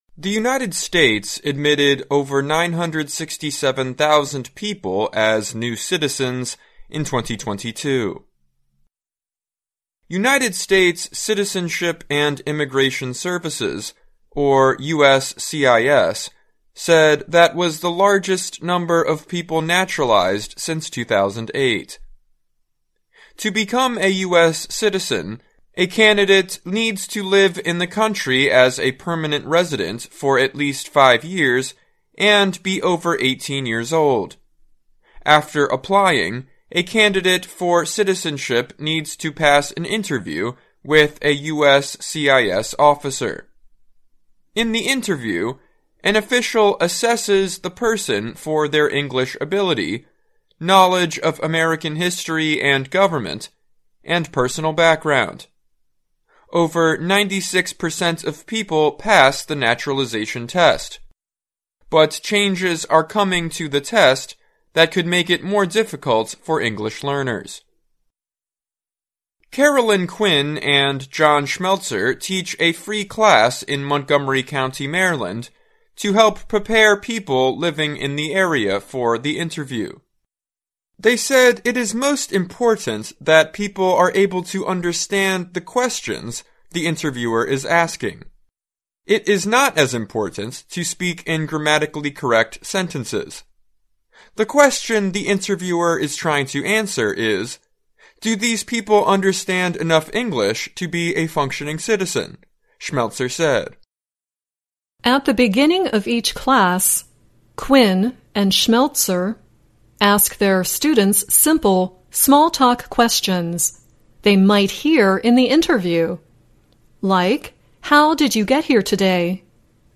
2023-09-20 [Education Report] Citizenship Test Changes Might Require Better English Skills
VOA慢速英语逐行复读精听提高英语听力水平